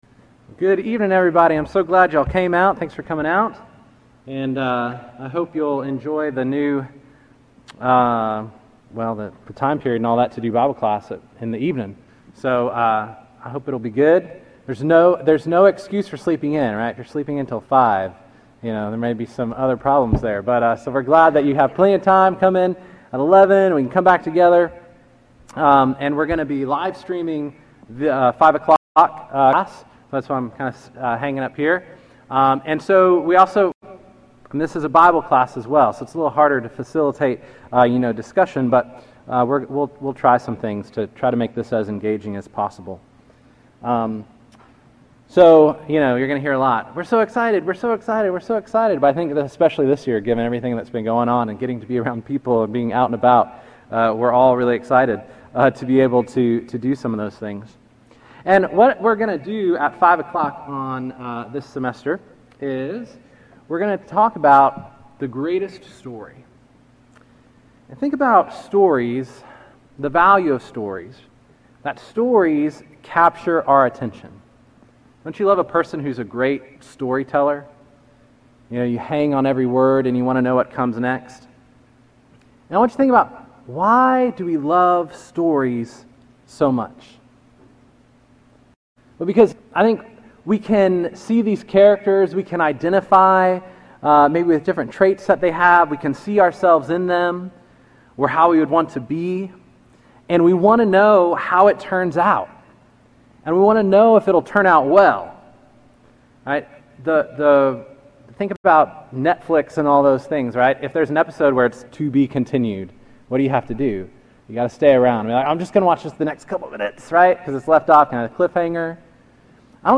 Week One of the University class called the Greatest Story.